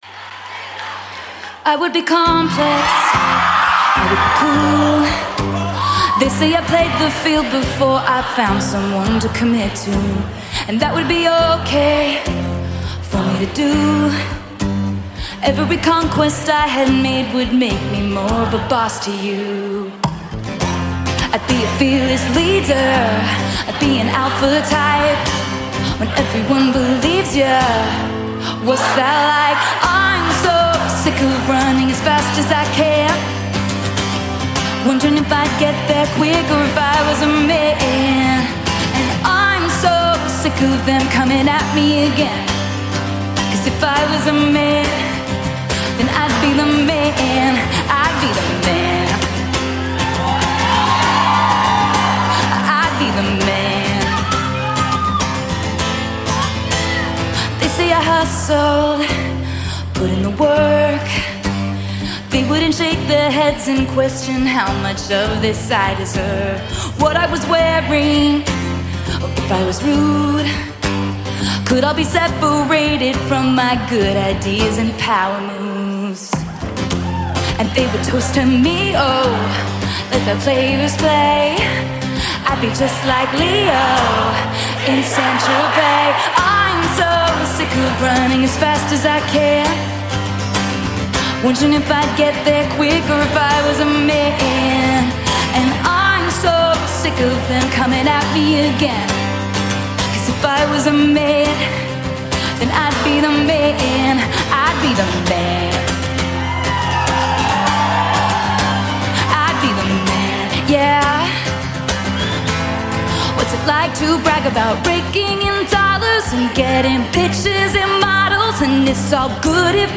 Live From Paris